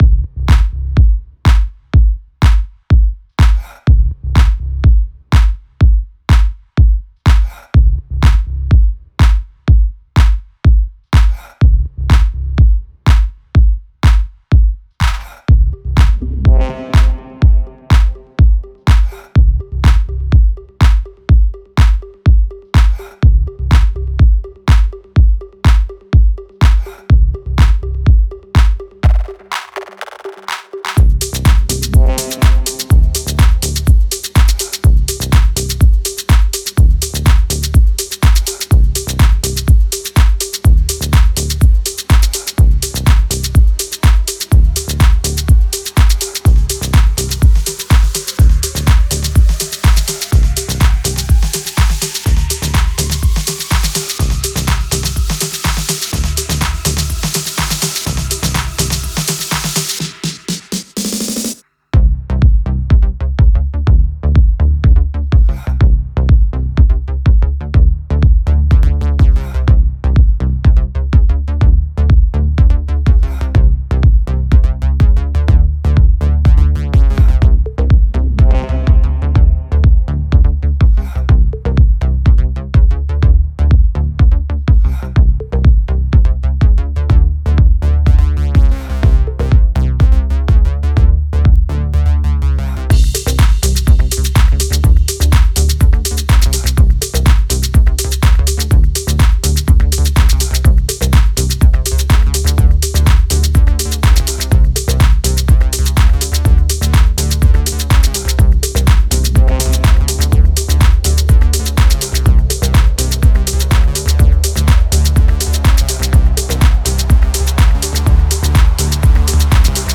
Nuit électronique. 3:12 AM.
Ondes techno irrésistibles. Rythme fluo dans lumière noire.